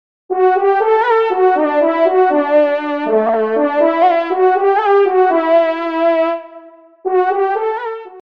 FANFARE
Extrait de l’audio « Ton de Vènerie »